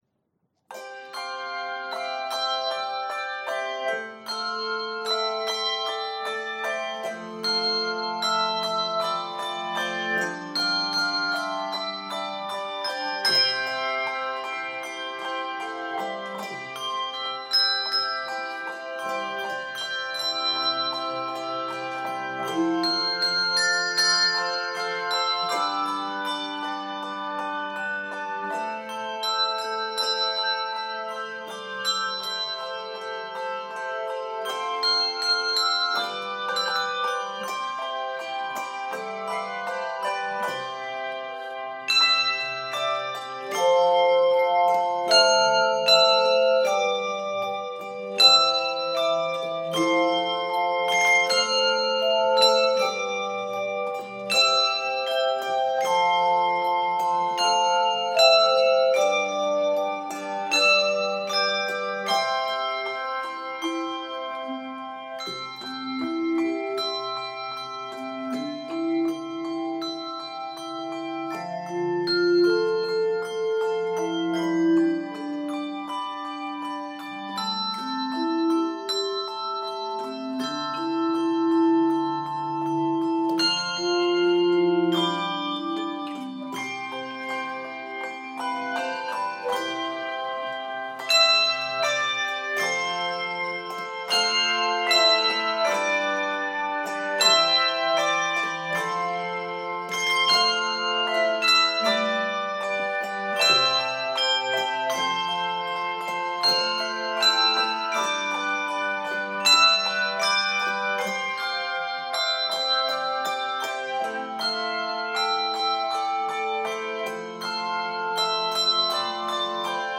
praise and worship song
Keys of C Major and F Major.